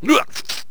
stickfighter_attack7.wav